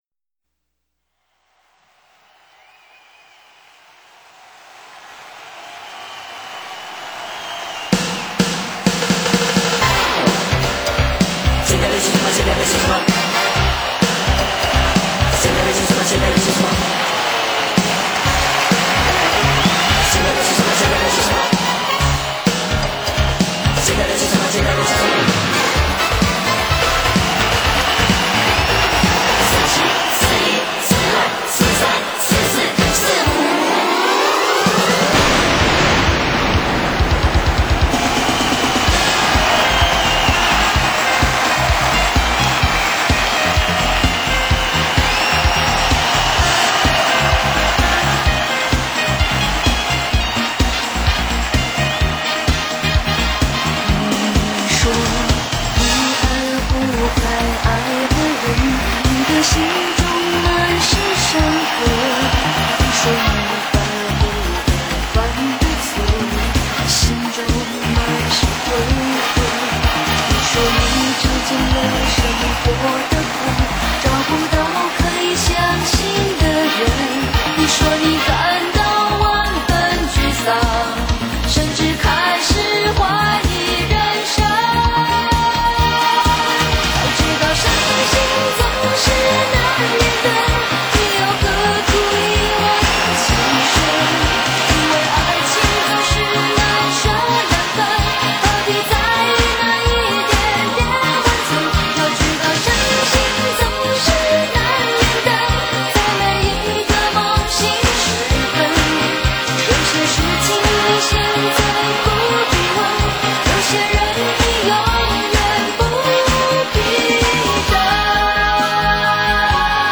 采45轉快轉的方式演唱串聯當紅歌曲的組曲